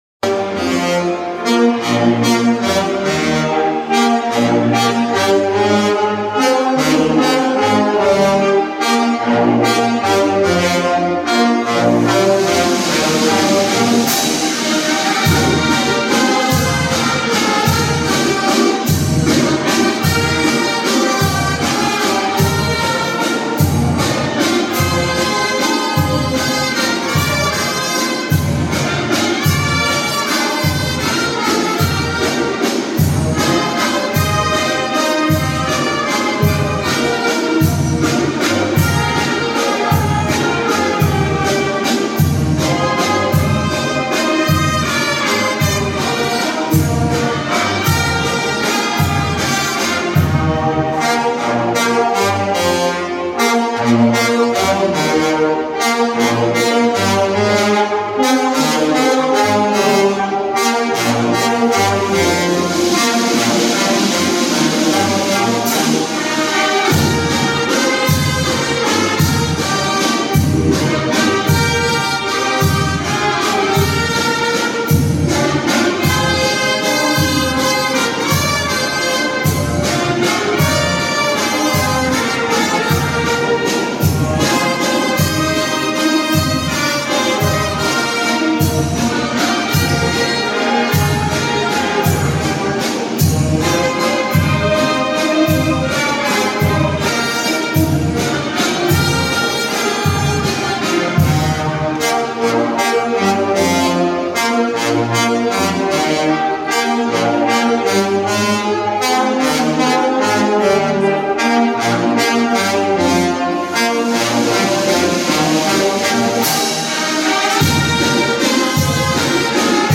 Música tradicional en Huautla de Jiménez, Oaxaca
Música para la celebración de la Guelaguetza en el mes de julio. e ensaya unos días antes de celebrar el natalicio de María Sabina, famosa curandera tradicional.
Equipo: Teléfono Oppo.